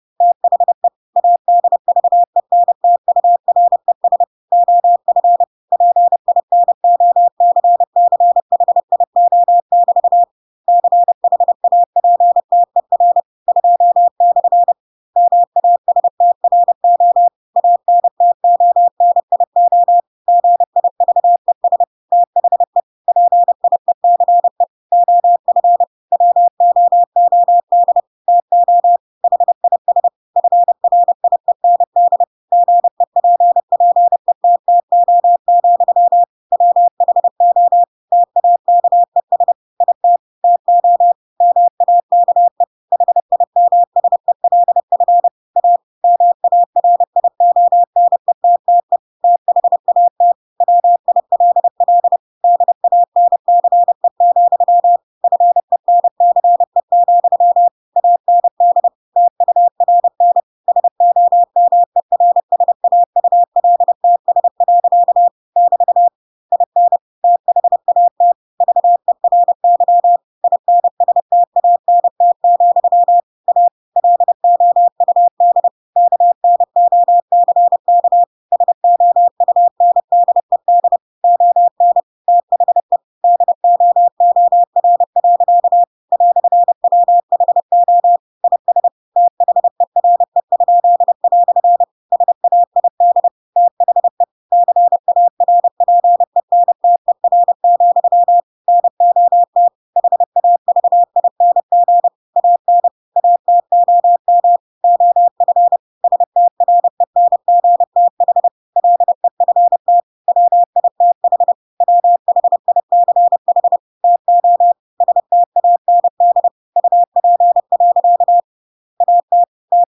Read a book in Morse code (CW)
Pinocchio ch 1 CW 10/25 wpm